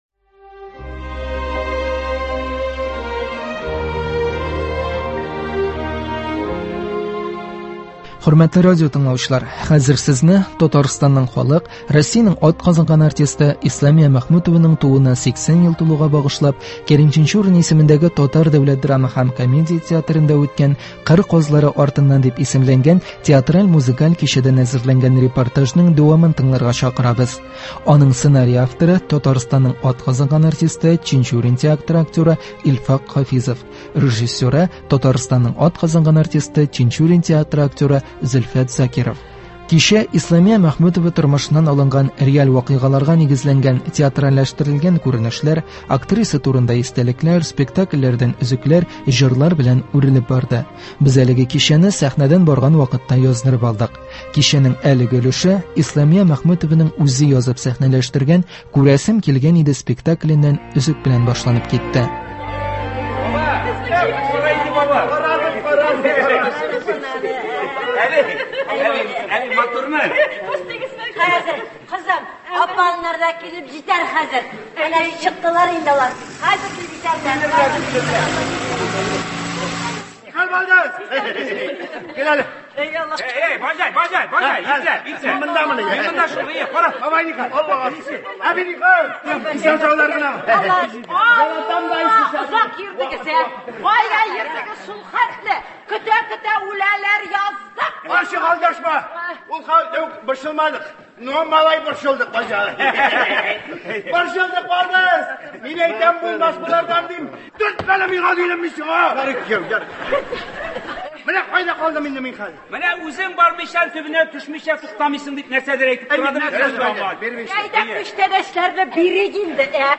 Татарстанның халык артисткасы Исламия Мәхмүтованы искә алу кичәсеннән репортаж. 1 нче өлеш.
Кичә Исламия Мәхмүтова тормышыннан алынган реаль вакыйгаларга нигезләнгән театральләштерелгән күренешләр, актриса турында истәлекләр, спектакльләрдән өзекләр, җырлар белән үрелеп барды. Без әлеге кичәне сәхнәдән барган вакытта яздырып алдык һәм хәзер игътибарыгызга тәкъдим итәбез.